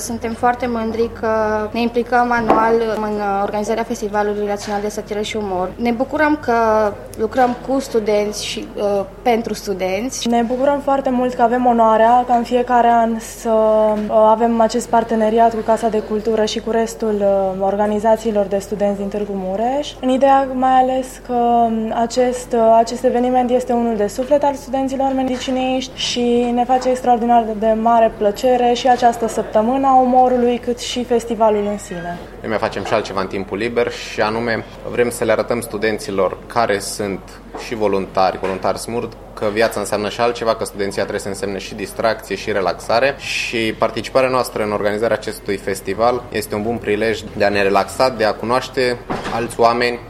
Reprezentanții asociațiilor studențești spun că festivalul le dă ocazia să cunoască alte persoane, dar și să le ofere studenților un alt fel de a petrece timpul liber: